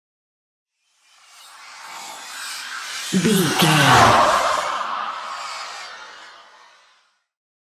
Airy pass by horror squeal
Sound Effects
In-crescendo
Atonal
ominous
haunting
eerie